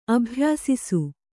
♪ abhyāsisu